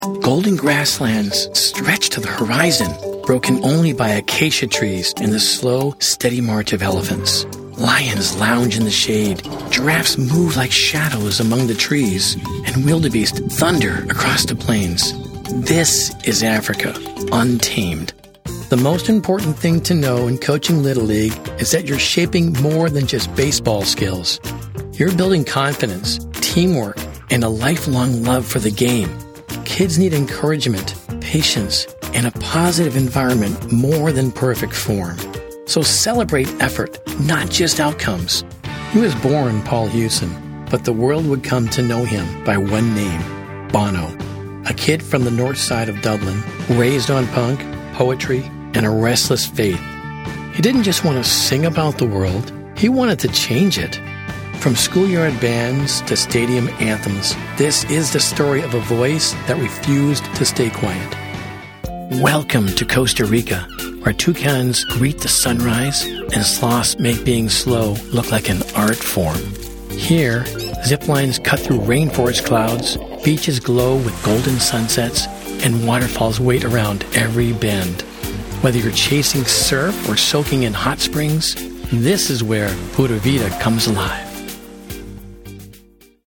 Foreign & British Male Voice Over Artists & Actors
Adult (30-50) | Older Sound (50+)